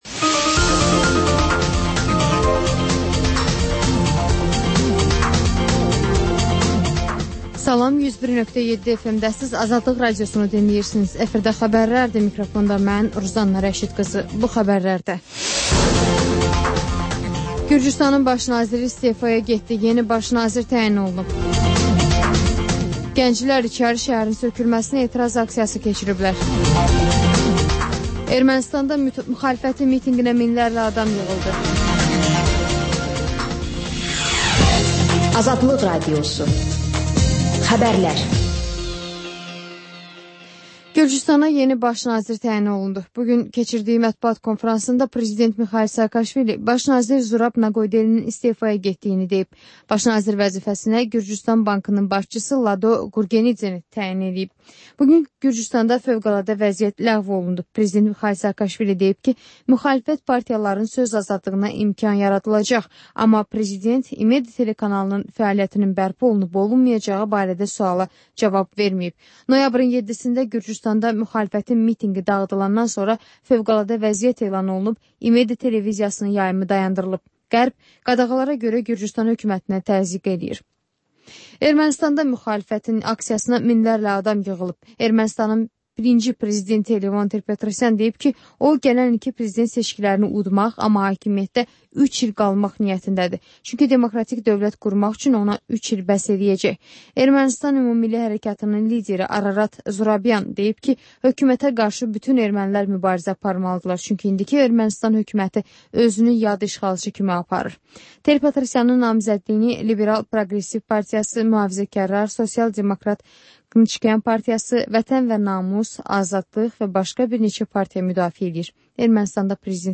Xəbərlər, müsahibələr, hadisələrin müzakirəsi, təhlillər, sonda XÜSUSİ REPORTAJ rubrikası: Ölkənin ictimai-siyasi həyatına dair müxbir araşdırmaları